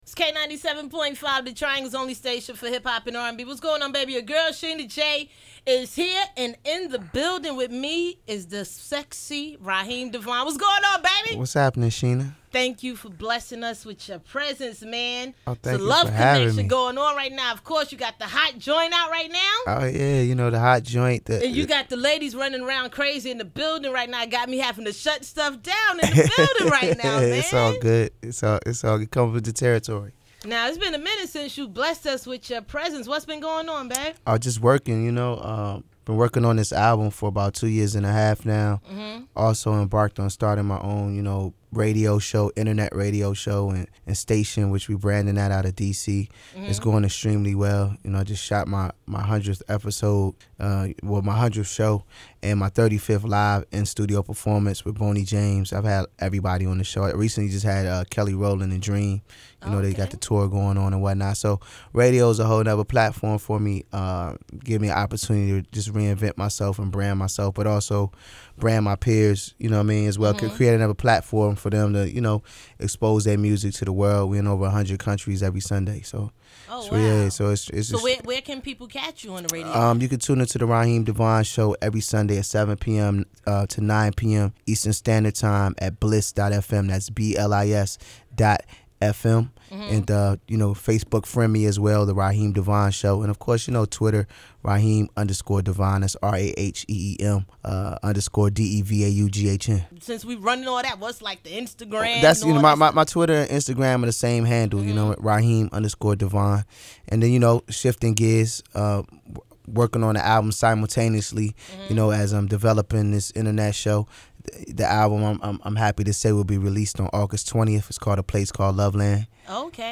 Check out the interview and don’t forget to pick up his new album August 20, “Welcome To Loveland.”
ravaughn-devaughn-interview.mp3